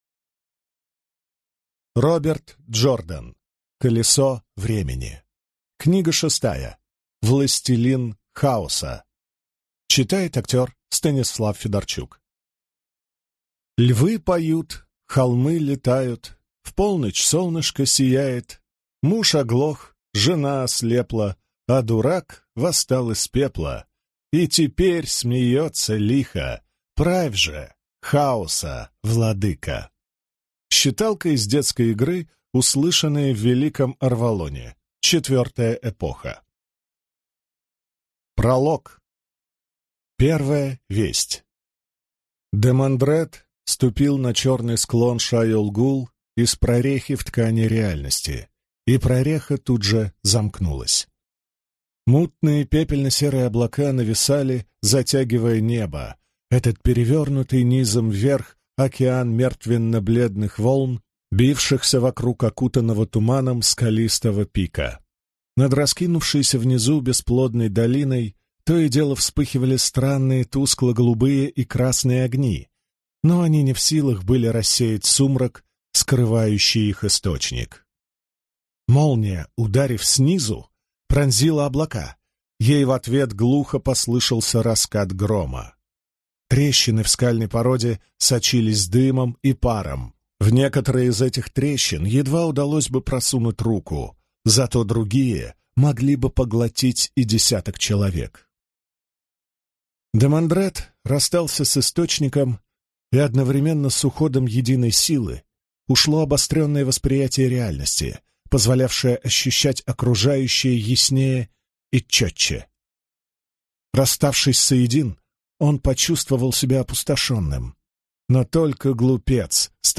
Аудиокнига Властелин хаоса | Библиотека аудиокниг